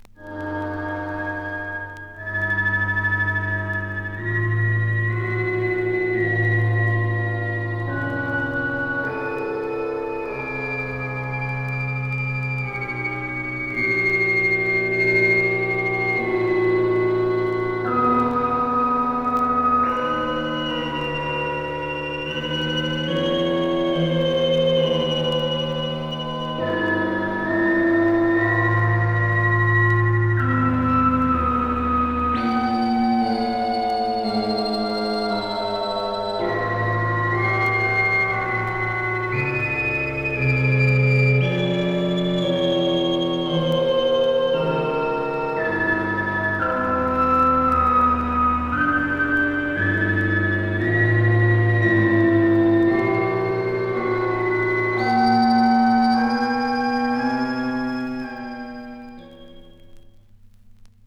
• phantom at the opera - scary organ sounds.wav
phantom_at_the_opera_-_scary_organ_sounds_rF6.wav